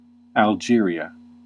Uttal
Uttal US RP: IPA : /ælˈdʒɪəriə/ Förkortningar (lag) Alg.